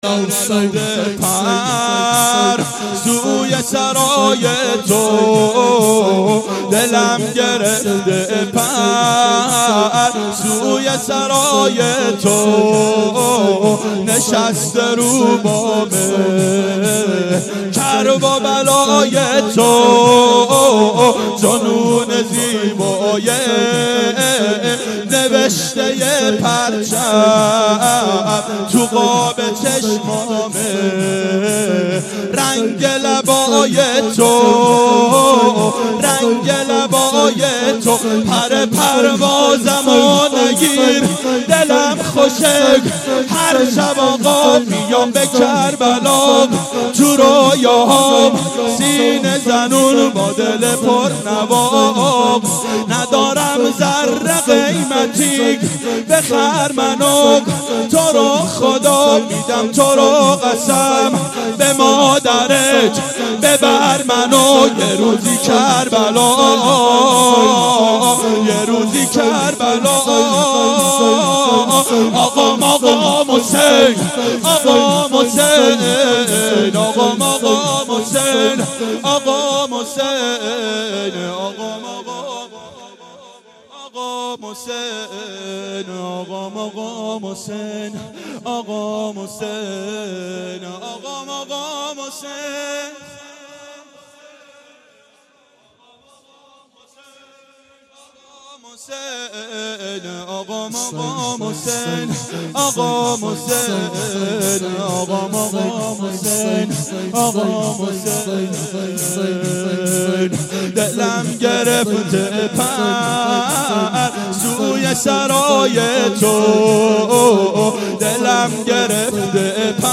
• شب اول محرم 92 هیأت عاشقان اباالفضل علیه السلام منارجنبان